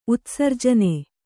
♪ utsarjane